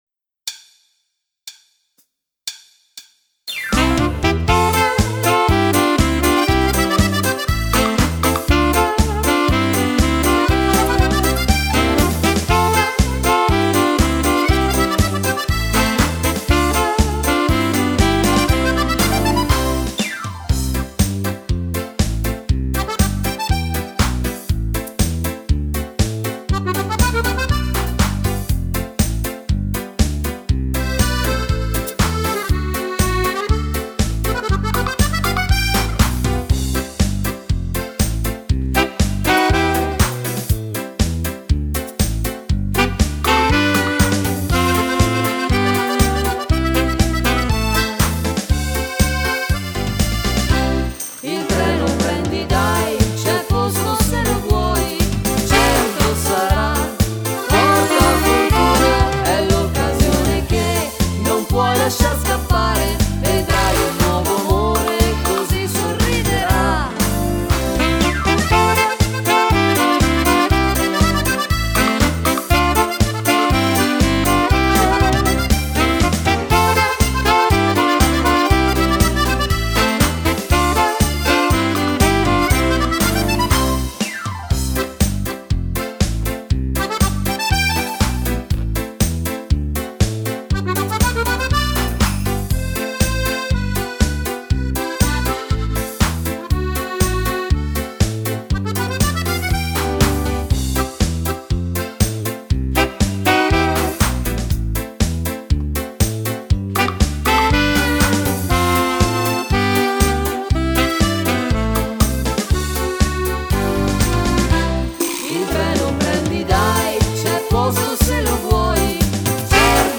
Ritmo allegro
Donna / Uomo